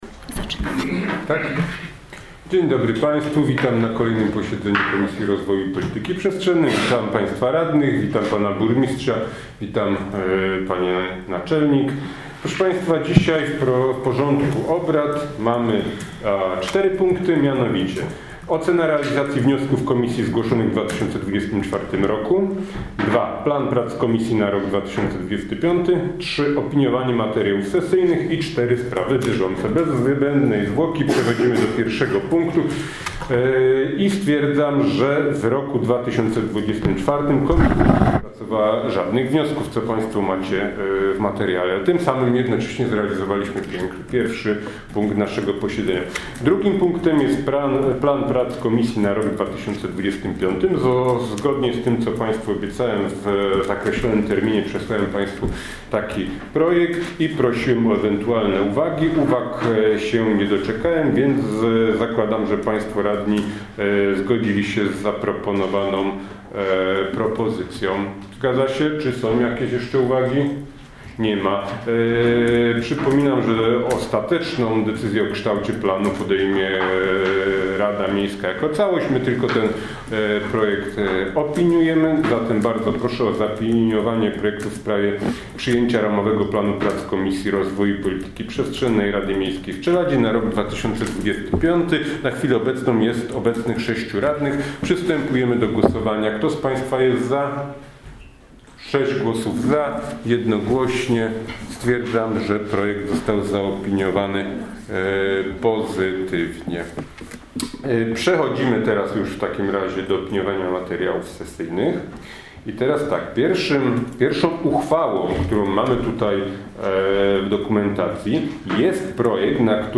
Posiedzenie Komisji Rozwoju i Polityki Przestrzennej w dniu 17 grudnia 2024 r.